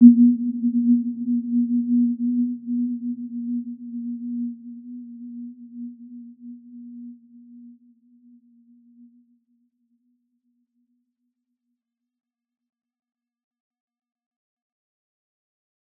Warm-Bounce-B3-mf.wav